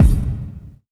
revgs_kick.wav